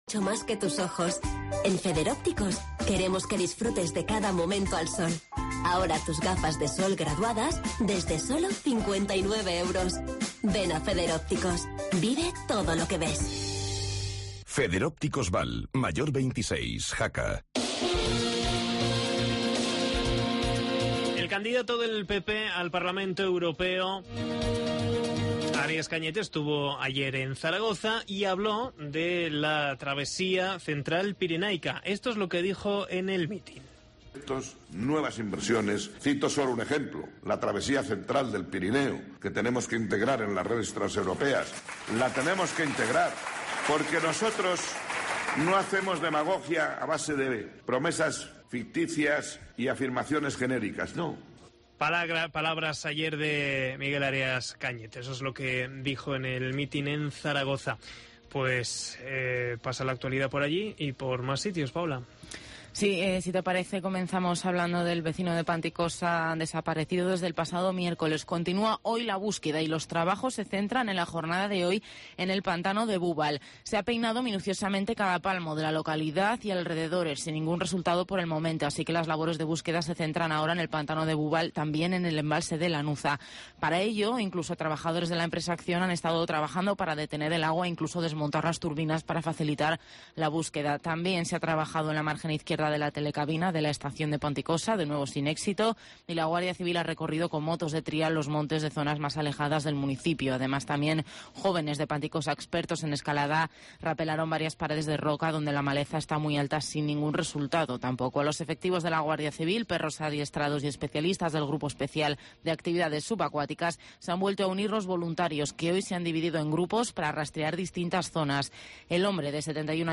AUDIO: La Mañana de Jaca con la actualidad de la jornada, conexión con el alcalde de Jaca Víctor Barrio desde Canfranc, entrevista a...